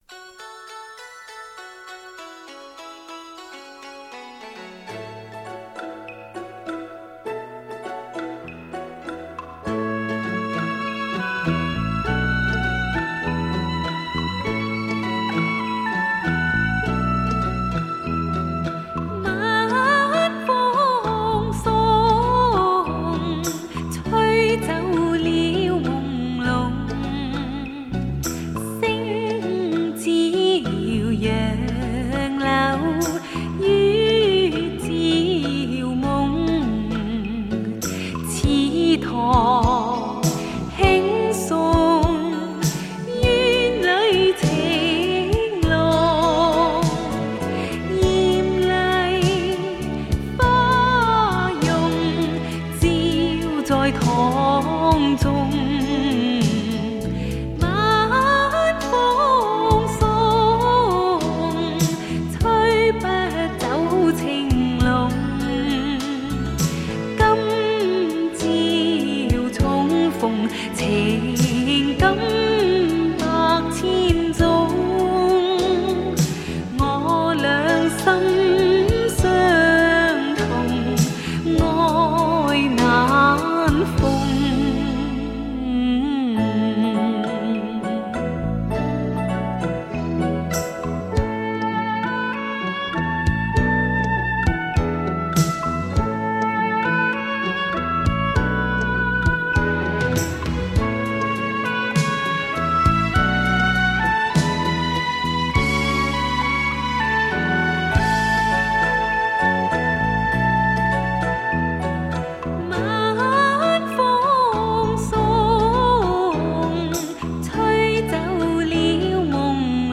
很浓很浓的粤剧气息，还渗透着江南旧恨。